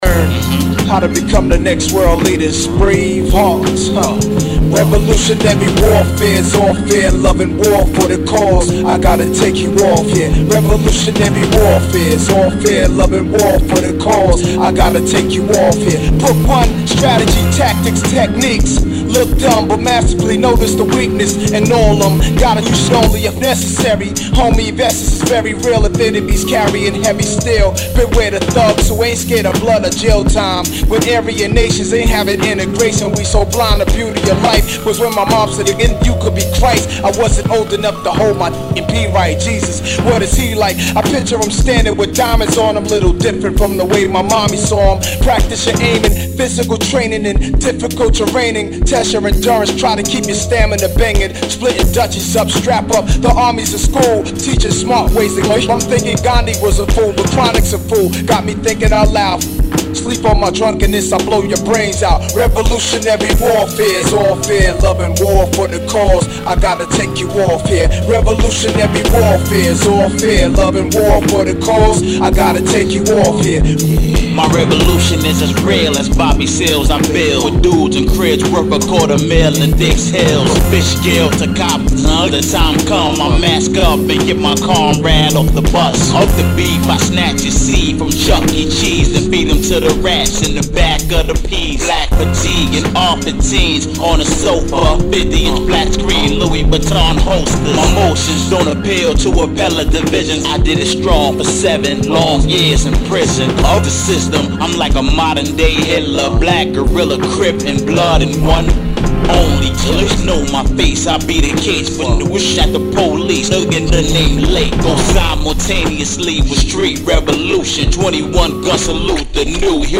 This Remix